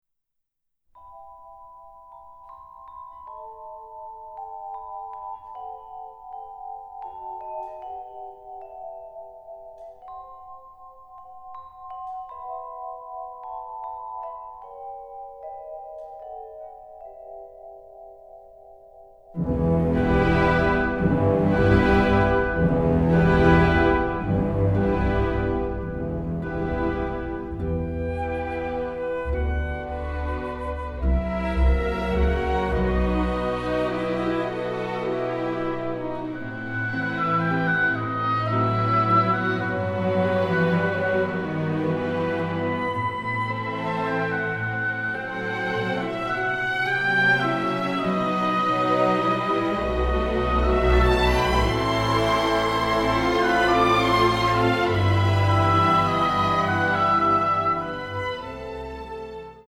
delightfully emotional
full of beautiful and delicate melodies